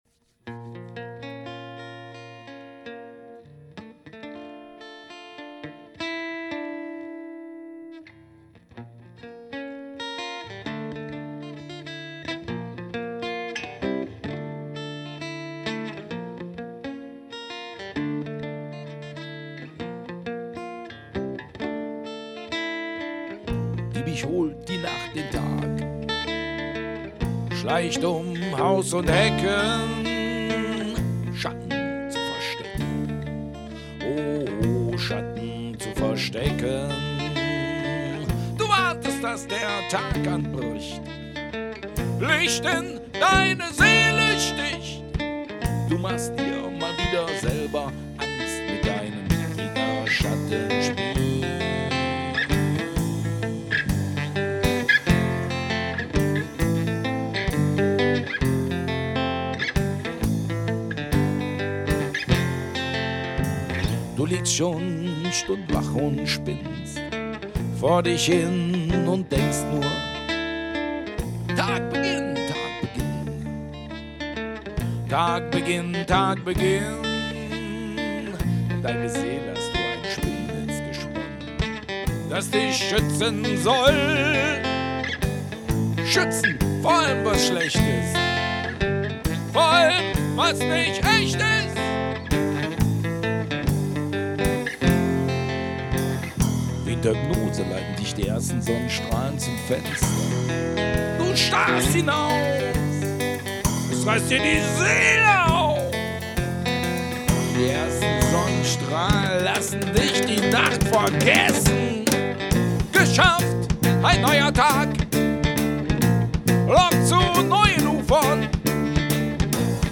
Unplugged Set - live aufgenommen im Studio